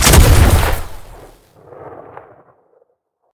rifle1.ogg